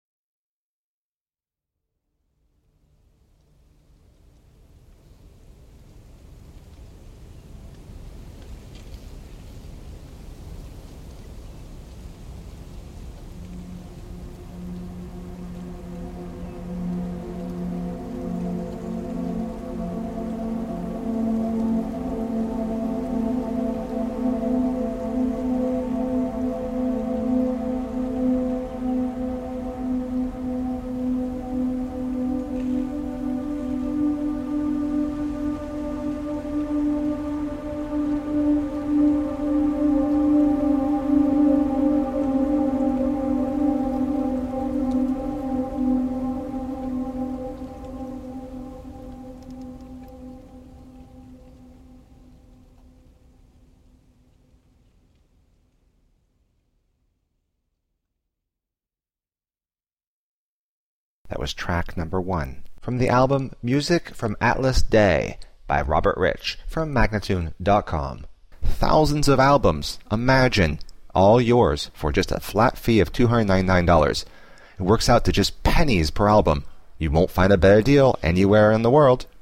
Groundbreaking ambient and dark-ambient.